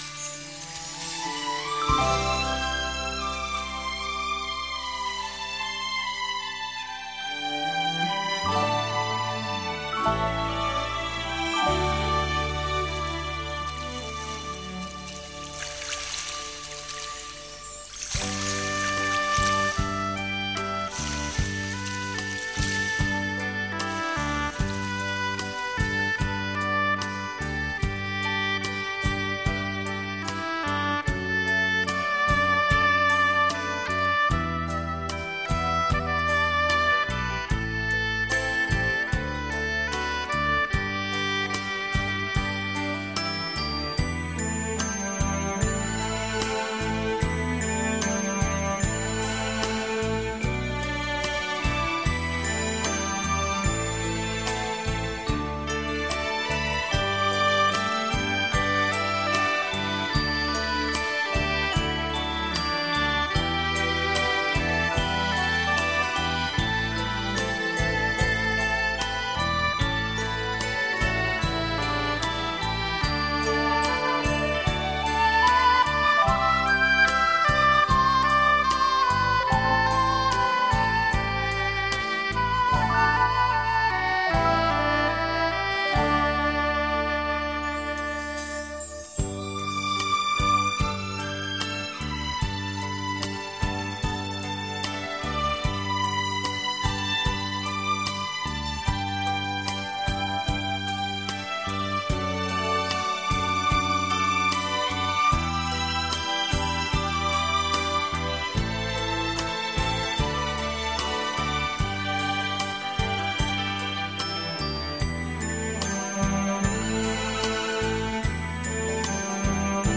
［慢四步］